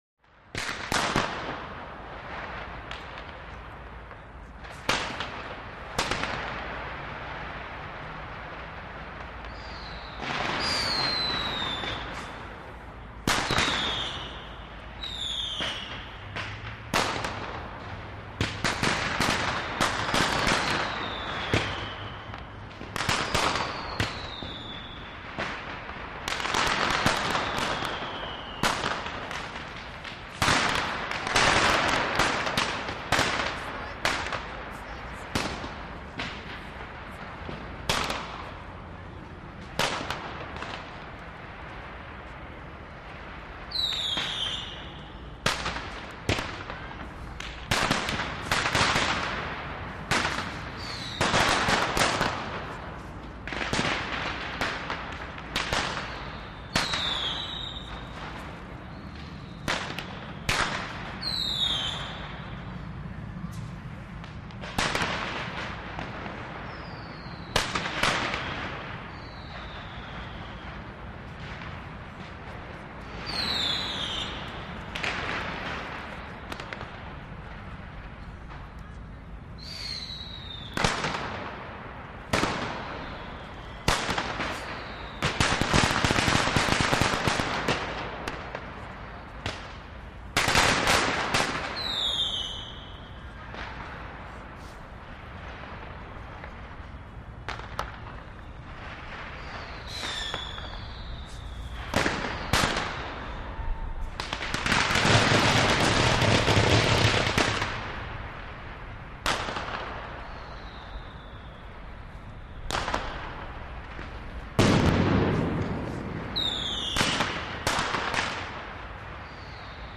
Fireworks; Sparse Compared To Track 1003-25. Firecrackers, Whistlers, Rockets Launch And Big Booms From Various Places Close To Very Distant. Echo And Reverberation From Tall Buildings, Lots Of